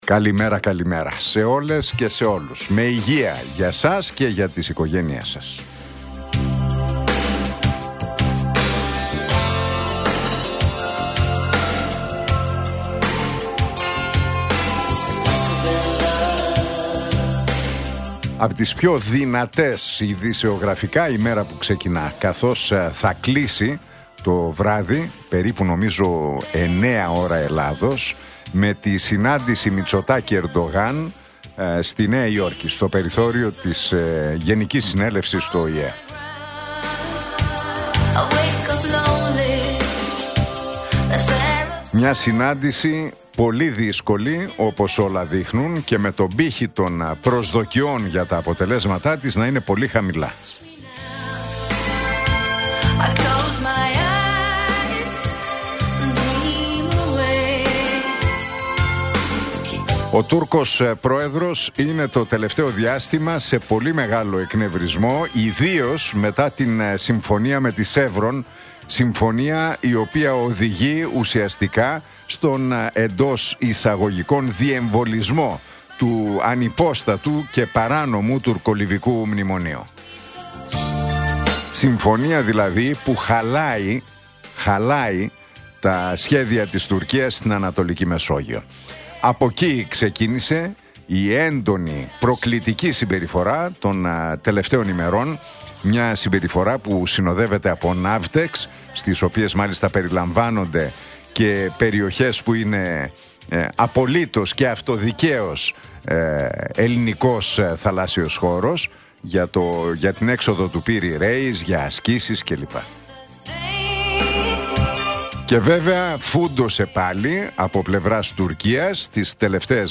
Ακούστε το σχόλιο του Νίκου Χατζηνικολάου στον ραδιοφωνικό σταθμό Realfm 97,8, την Τρίτη 23 Σεπτεμβρίου 2025.